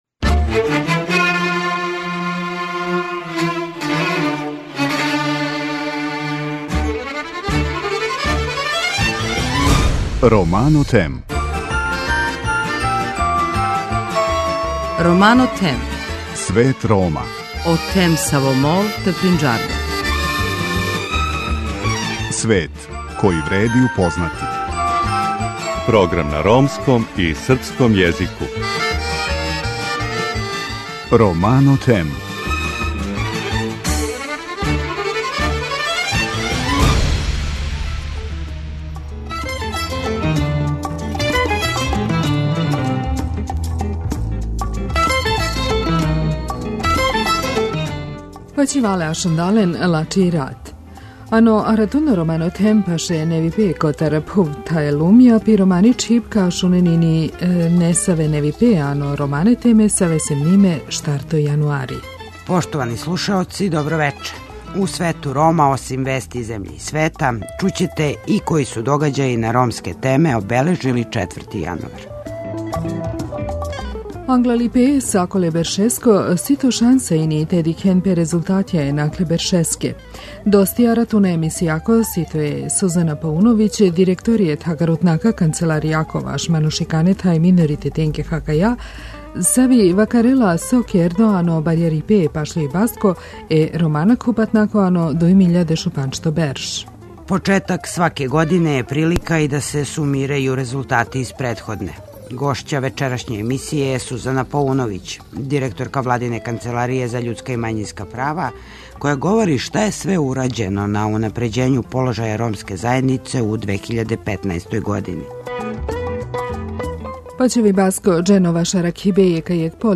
Почетак сваке године је прилика и да се сумирају резултати из претходне. Гошћа вечерашње емисије је Сузана Пауновић директорка владине канцеларије за људска и мањинска права која говори шта је све урађено на унапређењу положаја ромске заједнице у 2015. години.